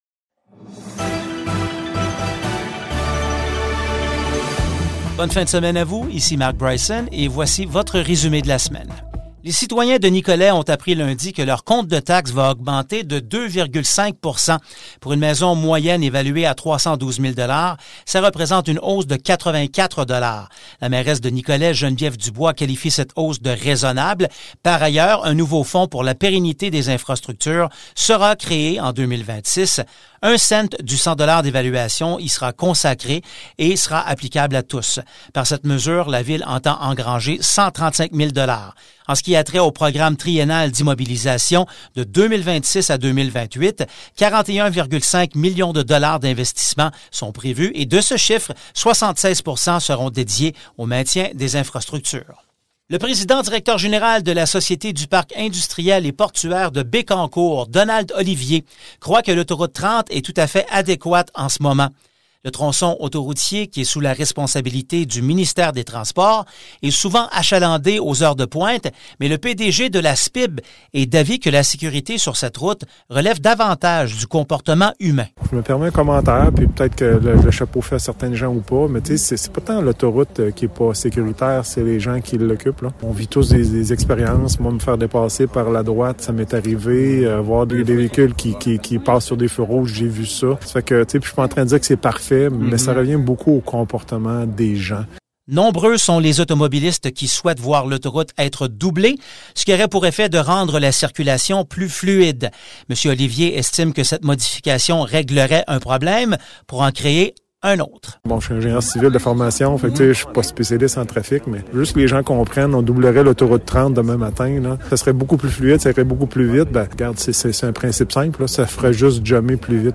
Ce résumé prend la forme d’un bulletin de nouvelles qui sera diffusé toutes les fins de semaine, et ce, dès maintenant.